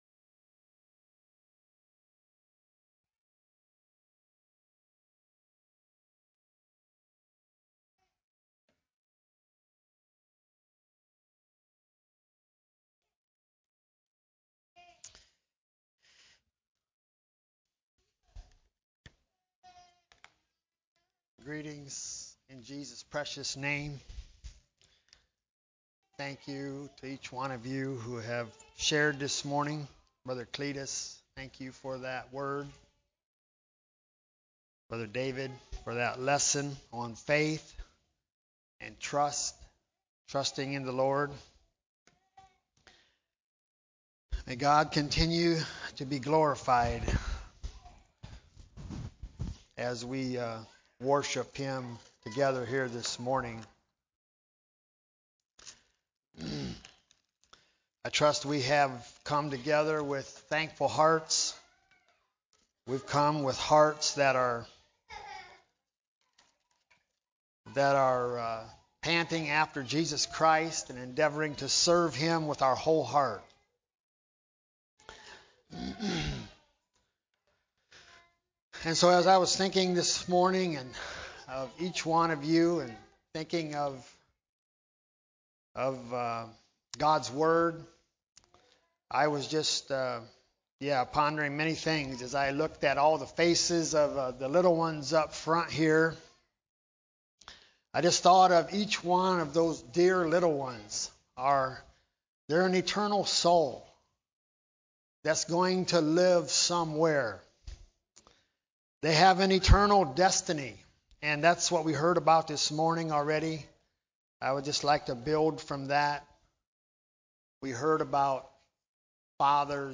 ACCF Sermons